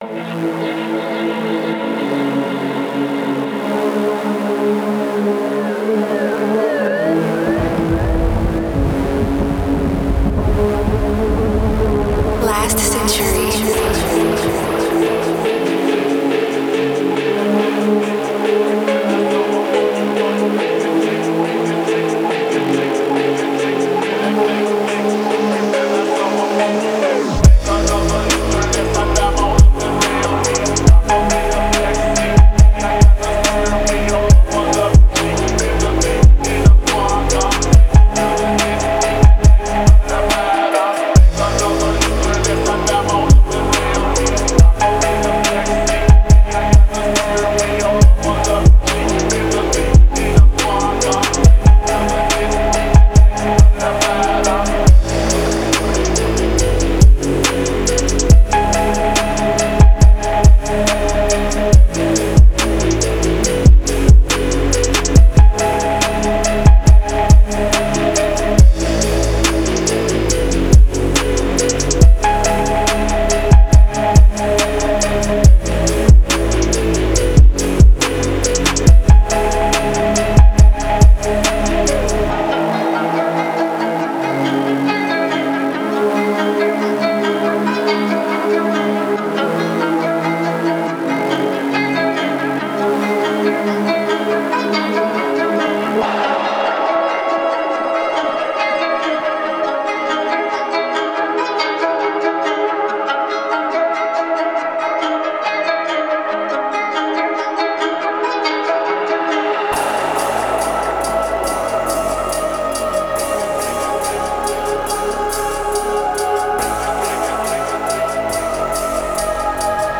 Фонк музыка
музыка с басами